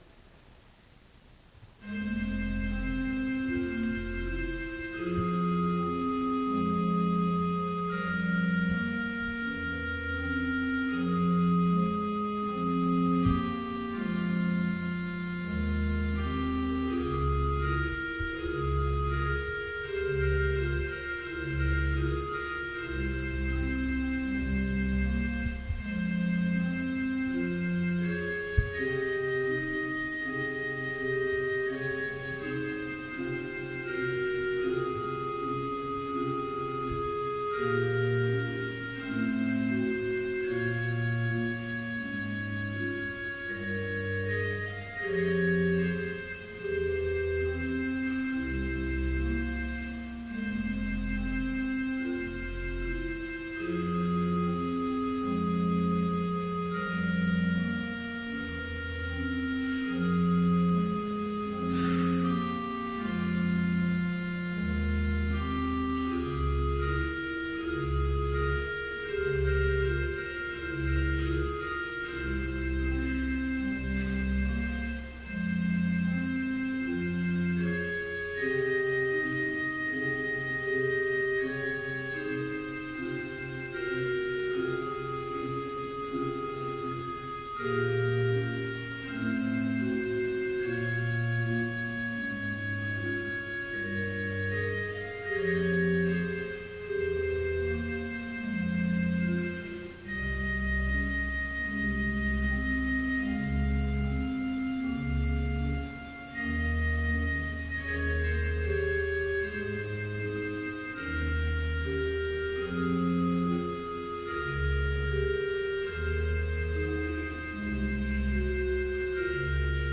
Complete service audio for Evening Vespers - October 1, 2014